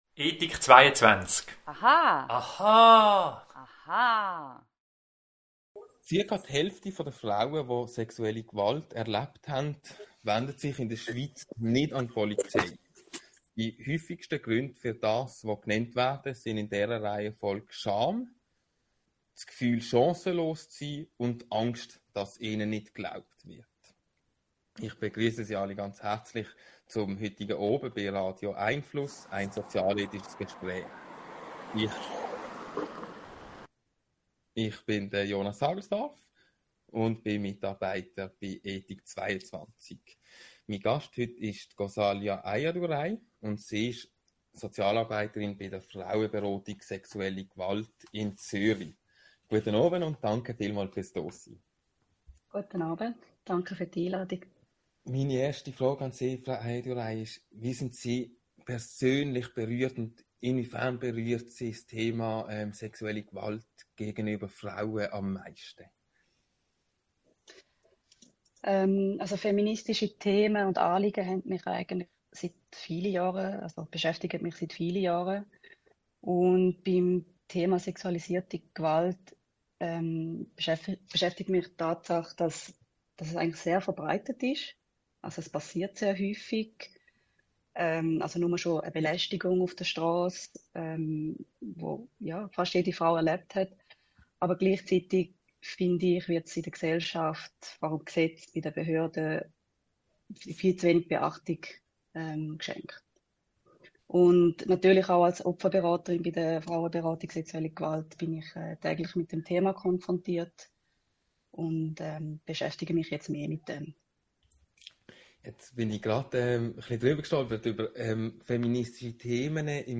Bleiben Sie über die kommenden Radio🎙einFluss Audio-Gespräche informiert!